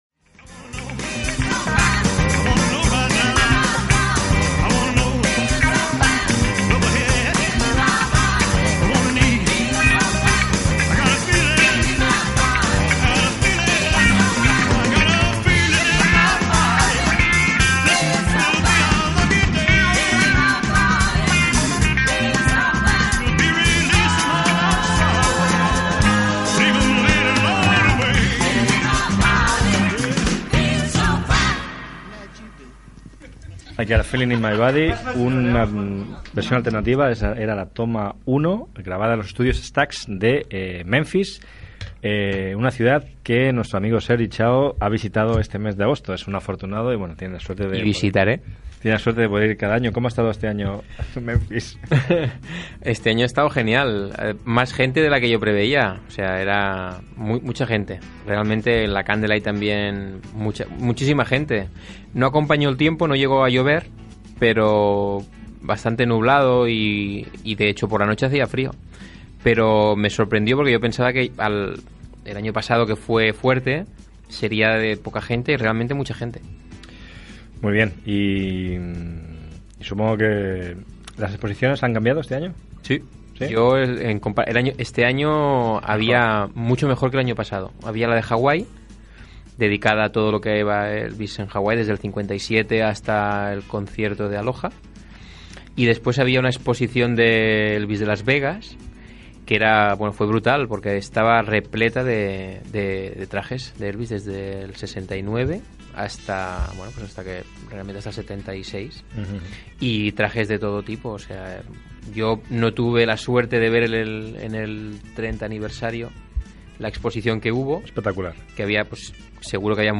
7e365a982f3402de69c278d2d2972dd53011c6fd.mp3 Títol Ràdio Ciutat Vella Emissora Ràdio Ciutat Vella Titularitat Tercer sector Tercer sector Barri o districte Nom programa Ritual nocturno Descripció Espai "La hora Elvis". Tema musical, comentari sobre una visita a Memphis, tema musical, comentari, tema musical, comentari i tema musical Gènere radiofònic Musical